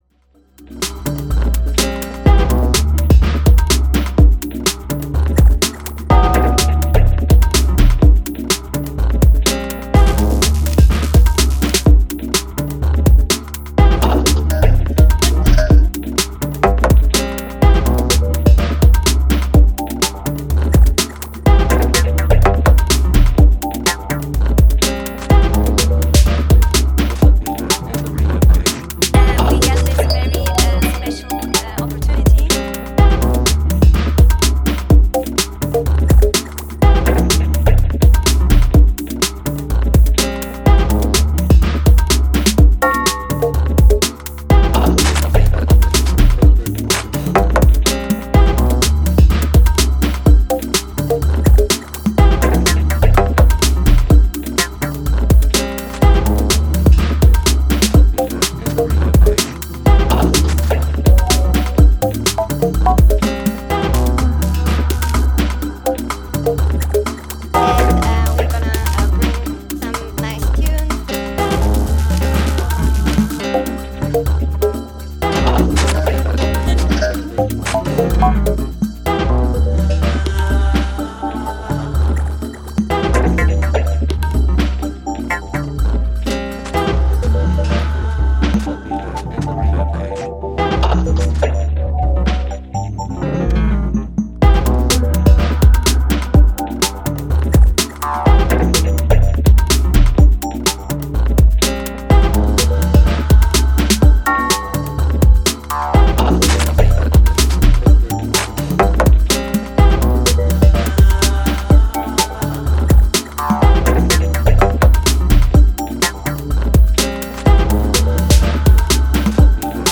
ソリッドなエレクトロ・ビートにサンプルを切り貼りした、絶妙にファニーな響き